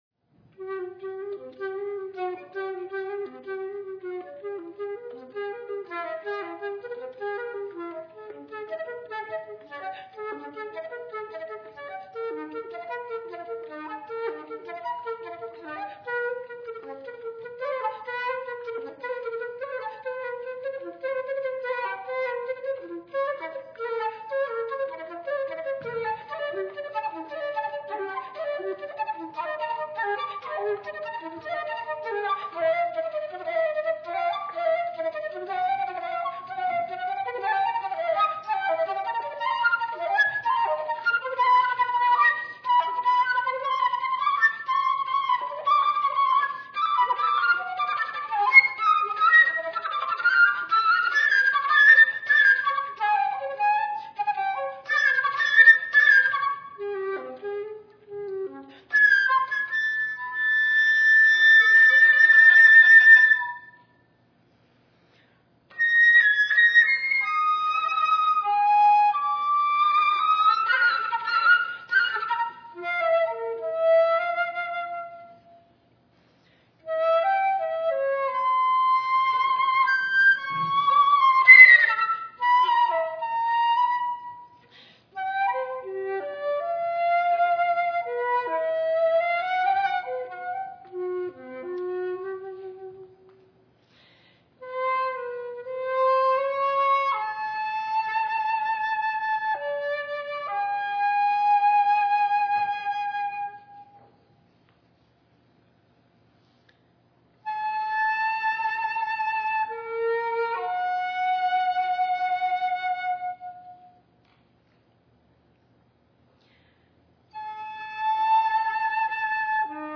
These are live recordings made somewhat on the fly.
Solo Flute